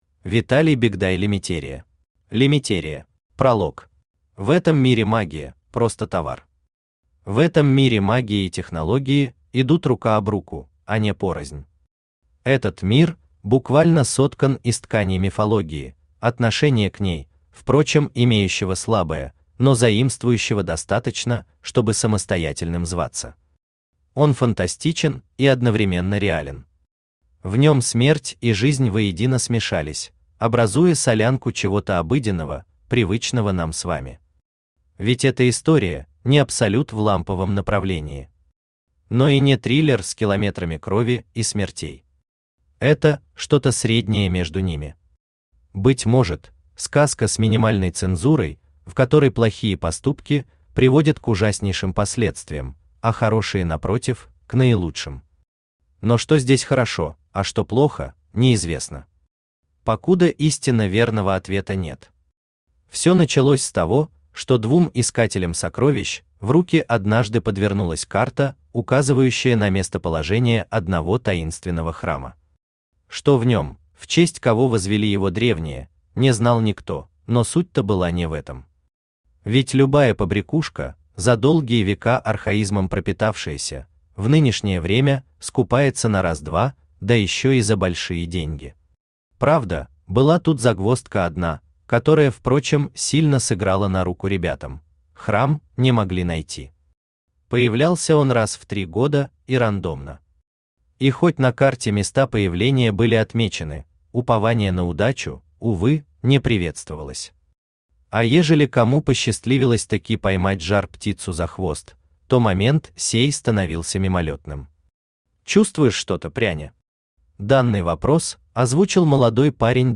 Aудиокнига Лимитерия Автор Виталий Андреевич Бегдай Читает аудиокнигу Авточтец ЛитРес.